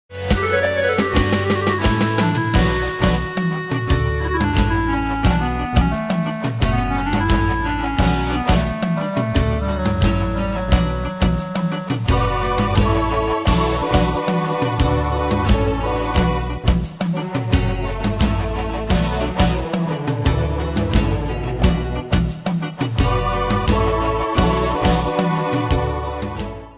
Style: PopFranco Ane/Year: 1994 Tempo: 88 Durée/Time: 3.22
Danse/Dance: PopRock Cat Id.
Pro Backing Tracks